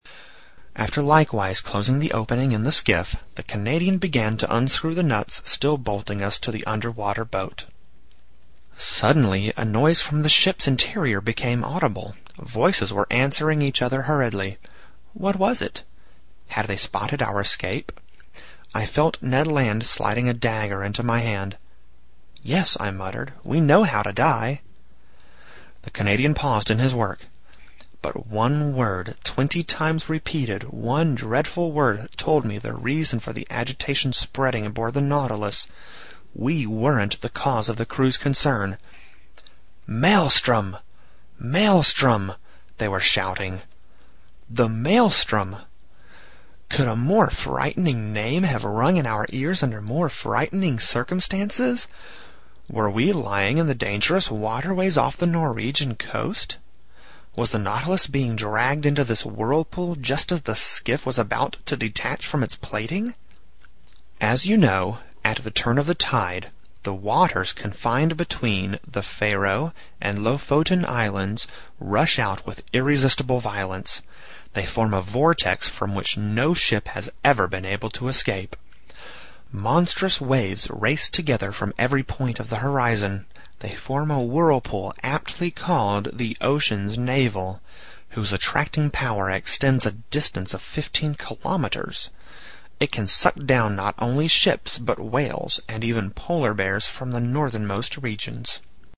在线英语听力室英语听书《海底两万里》第563期 第35章 尼摩船长的最后几句话(10)的听力文件下载,《海底两万里》中英双语有声读物附MP3下载